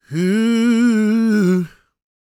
GOSPMALE019.wav